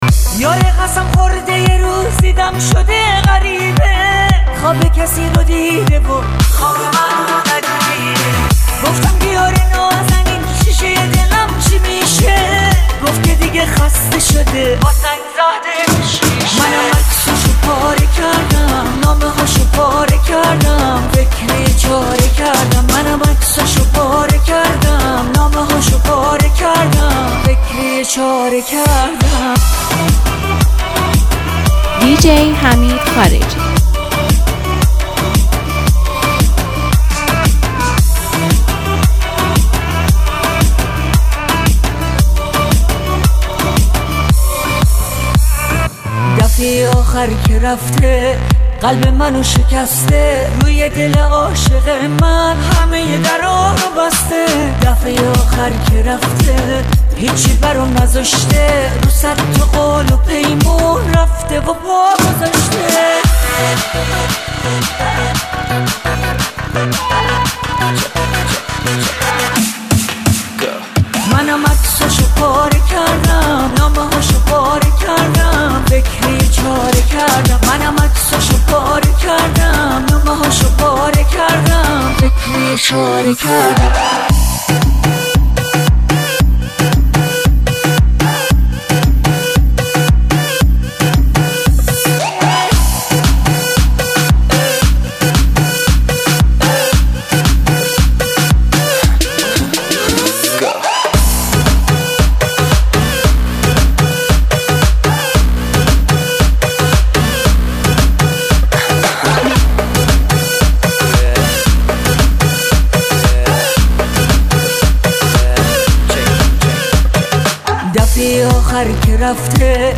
ریمیکس زیبای